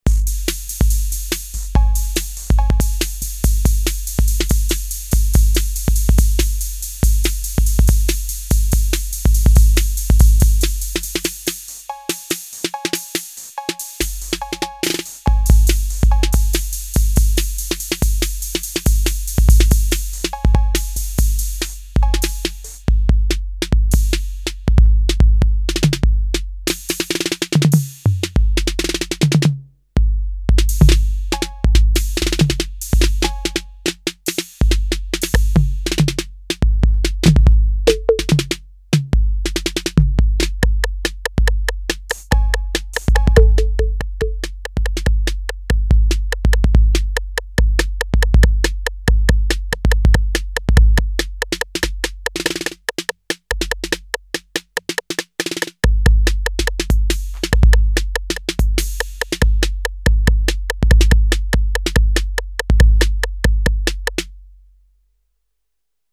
computer controlled drum machine
The voice board generates 16 analog percussions which 8 of them are circuit-shared.
demo AUDIO DEMO
demo pattern rhythm
demo jam